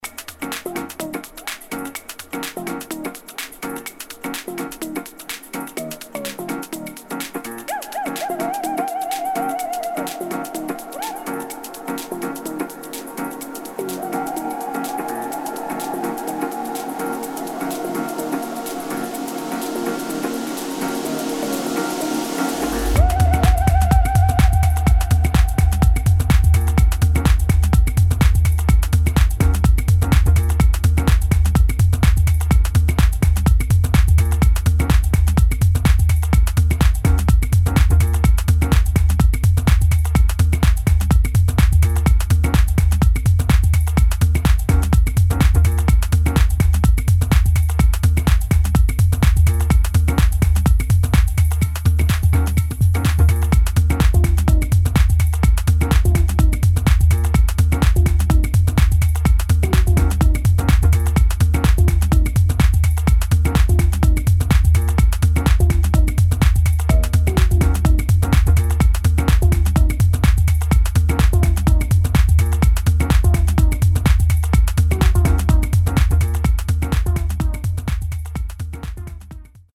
[ DEEP HOUSE | TECH HOUSE ]